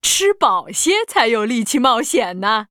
文件 文件历史 文件用途 全域文件用途 Balena_fw_01.ogg （Ogg Vorbis声音文件，长度2.7秒，101 kbps，文件大小：33 KB） 源地址:游戏语音 文件历史 点击某个日期/时间查看对应时刻的文件。